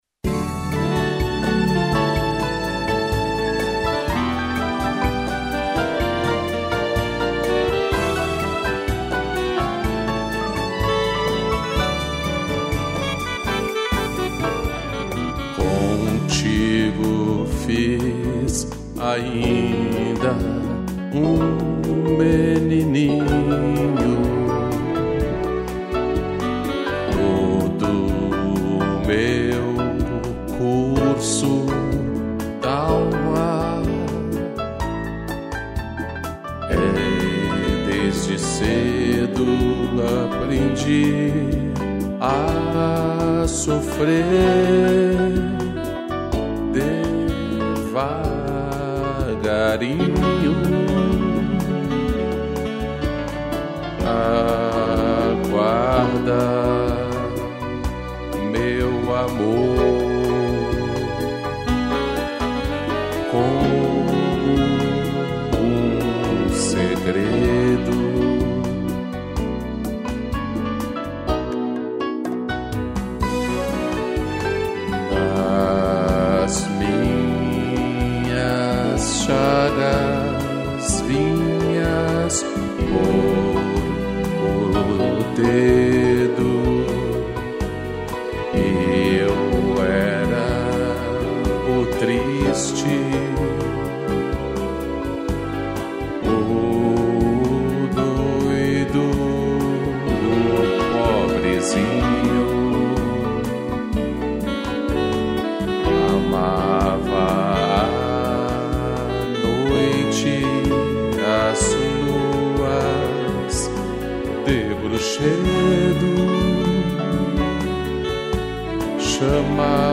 piano, strings e sax